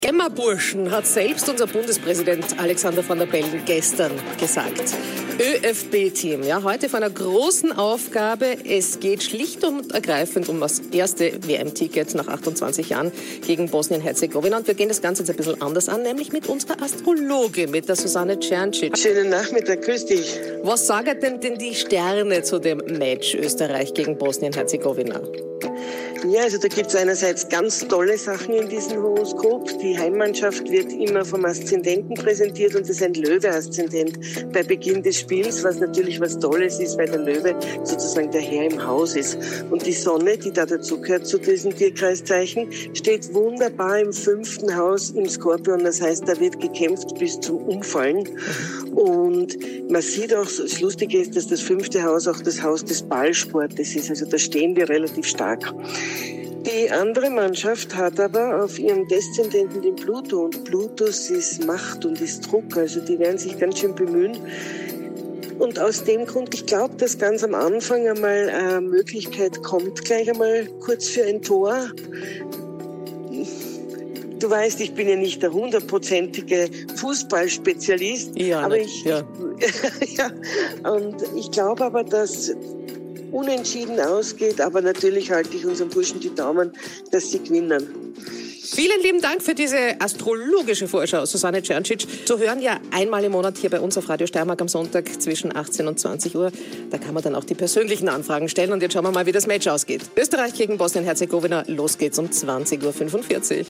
Interview VOR dem Fussball-Länderspiel Österreich gegen Bosnien!!!
Interview__Oesterreich_Bosnien.mp3